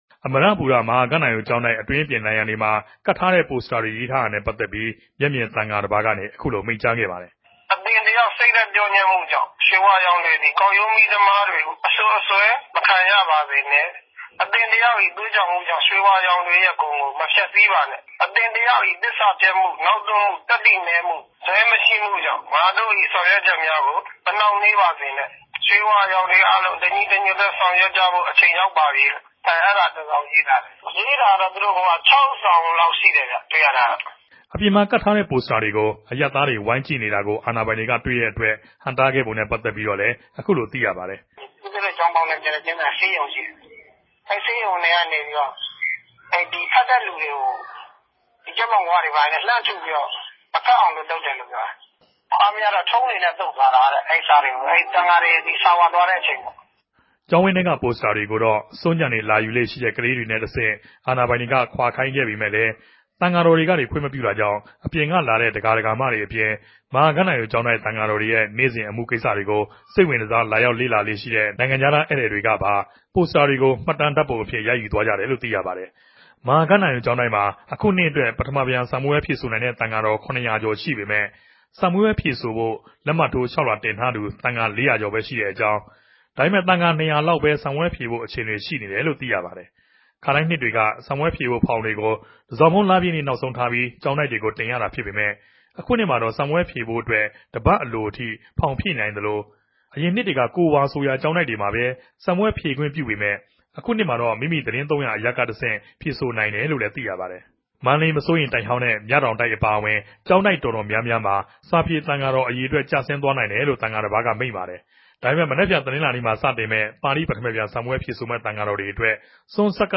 မဵက်ူမင်သံဃာတော်တပၝး။ ။ "ကပ်ထားတဲ့စာတေကြတော့ စာမေးပြဲမေူဖဖိုႛဟာတေကြို အဓိကလံြႚဆော်ရေးထားတဲ့ စာတေပြဲ၊ စက်တင်ဘာတုန်းက သံဃာတေကြို ဂုဏ်ူပရြည်ႌြန်းထားတဲ့ ကဗဵာတြေရောပၝတယ်၊ အဲဒီမြာရေးထားုကတာတြေ အဓိကတော့ စာမေးပြဲမေူဖဖိုႛနဲႛ ဋ္ဌကီးုကပ်တဲ့ဆရာတော်တေကြိုလည်း မသြားုကဖိုႛရေးထားတယ်။"